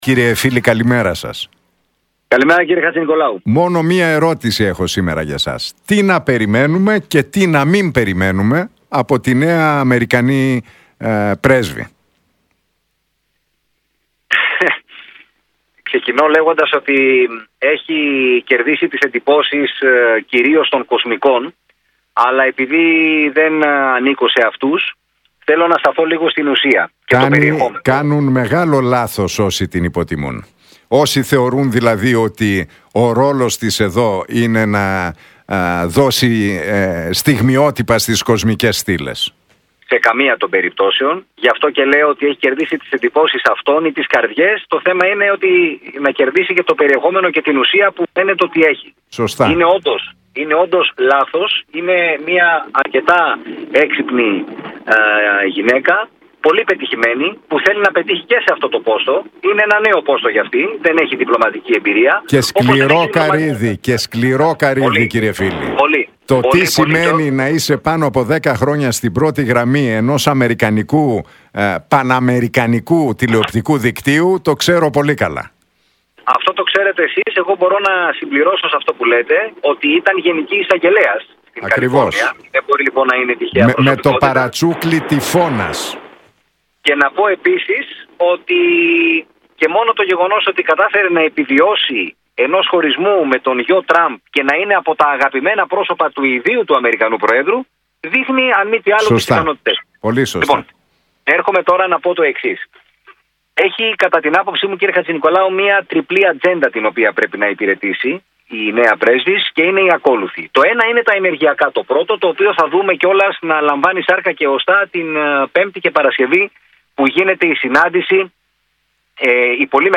Για την πρέσβη των ΗΠΑ στην Ελλάδα, Κίμπερλι Γκιλφόιλ και την ατζέντα την οποία θα προωθήσει μίλησε ο διεθνολόγος, Κωνσταντίνος Φίλης στην εκπομπή του Νίκου Χατζηνικολάου στον Realfm 97,8.